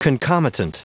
Prononciation du mot concomitant en anglais (fichier audio)
Prononciation du mot : concomitant